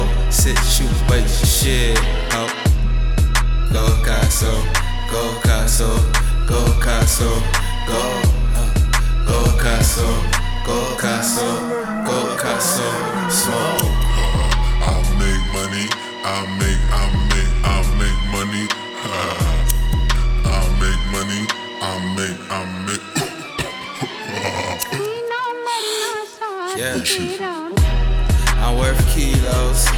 Жанр: Хип-Хоп / Рэп
Hip-Hop, Rap